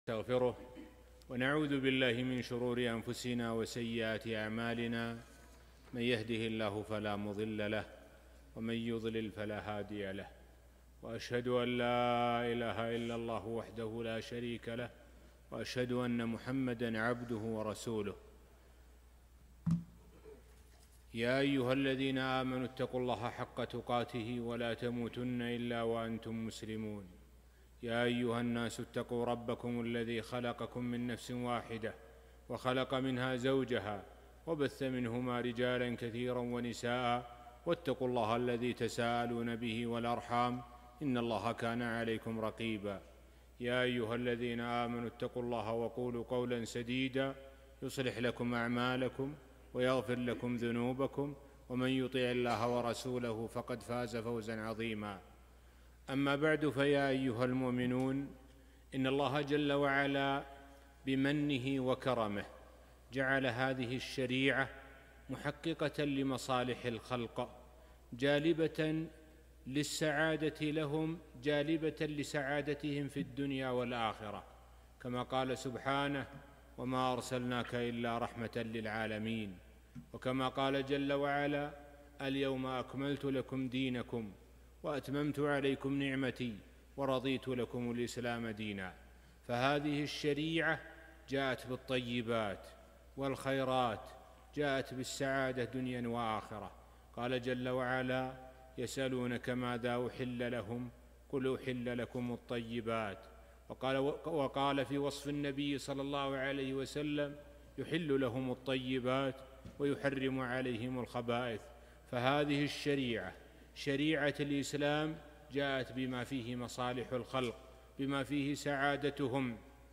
محاضرة - حلول المشاكل الزوجية